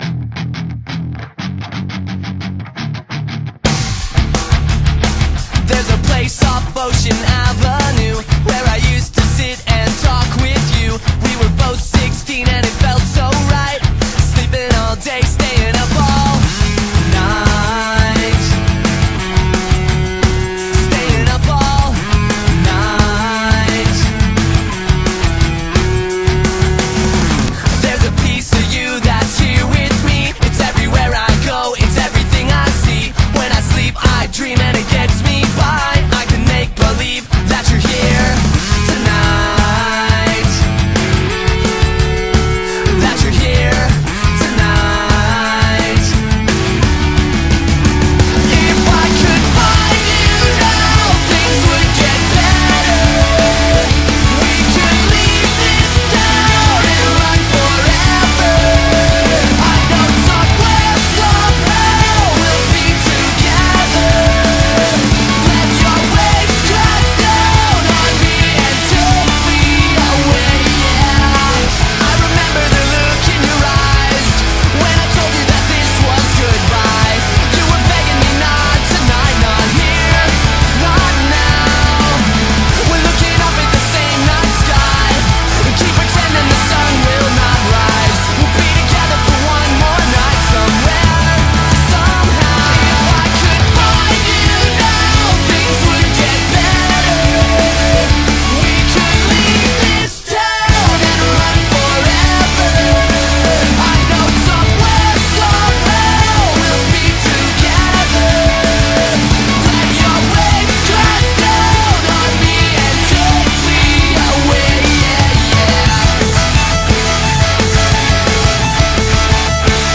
BPM164-174
Audio QualityPerfect (Low Quality)